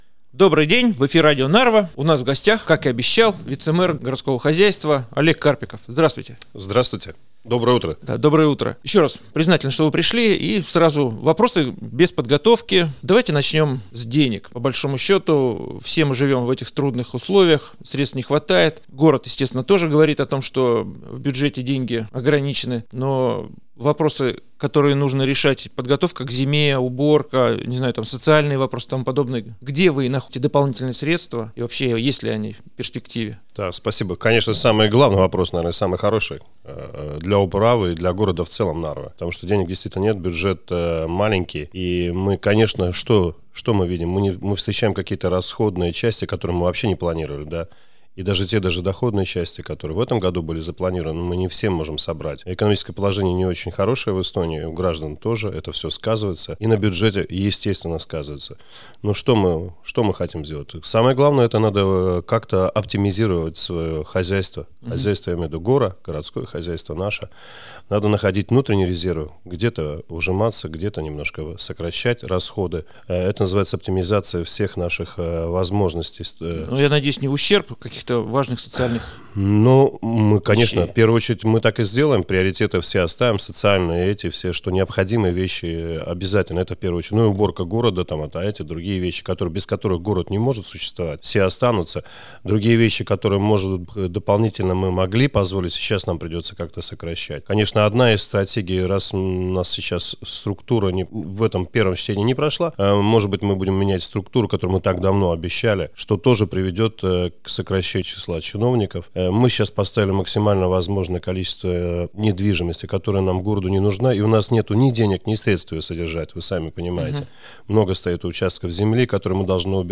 Вице-мэр по городскому хозяйству Олег Карпиков отвечает на наши вопросы по вторникам.